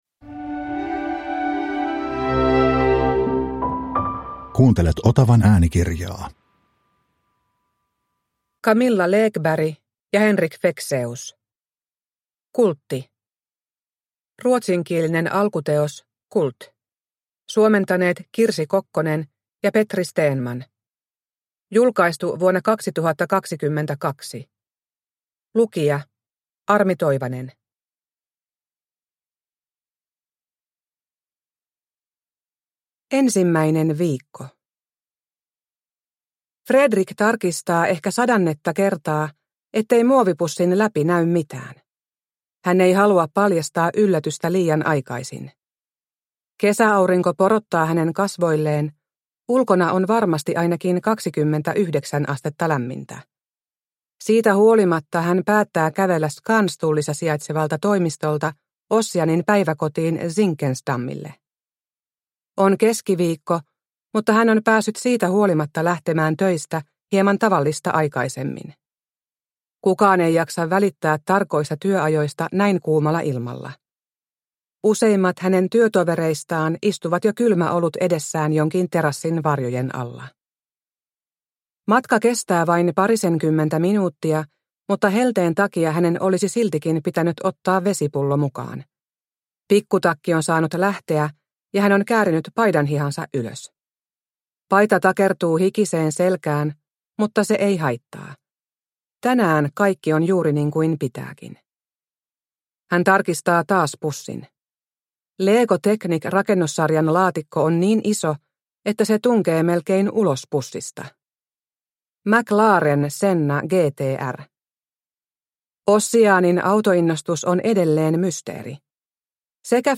Kultti – Ljudbok – Laddas ner